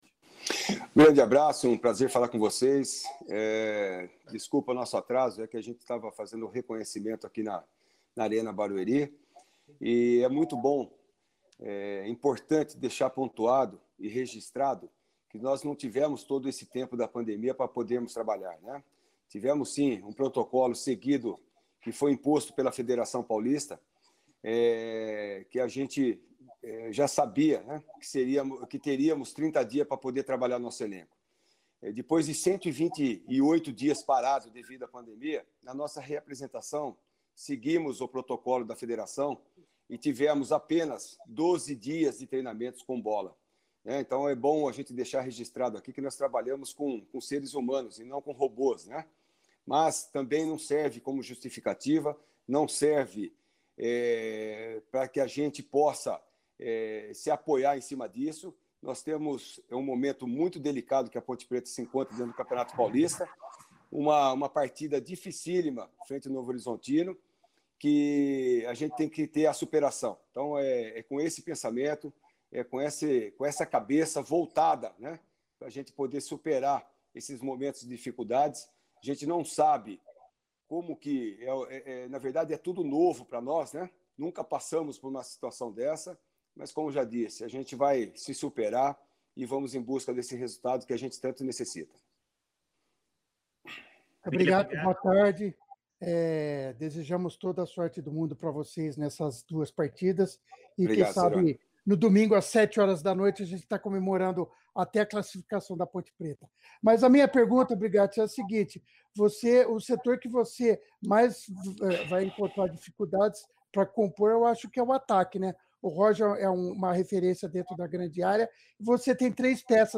“ Confira abaixo a entrevista na íntegra logo abaixo. http